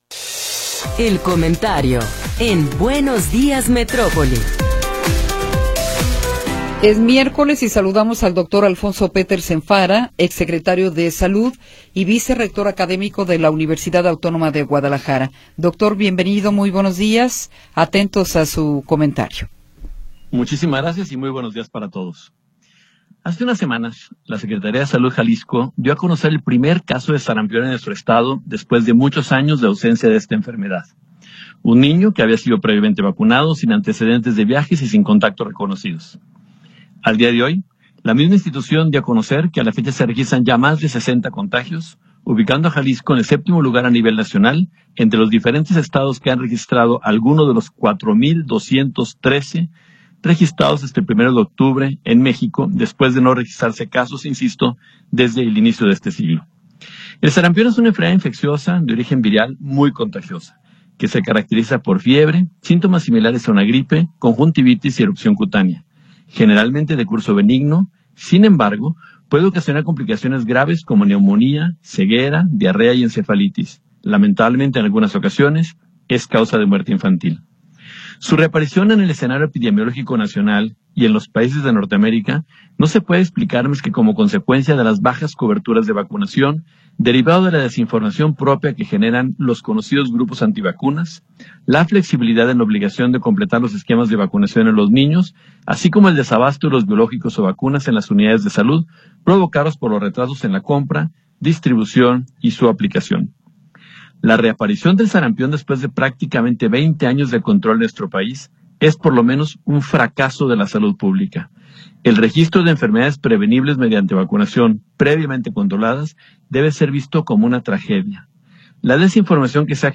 Comentario de Alfonso Petersen Farah
El Dr. Alfonso Petersen Farah, vicerrector de ciencias de la salud de la UAG y exsecretario de salud del estado de Jalisco, nos habla sobre los brotes de sarampión en Jalisco.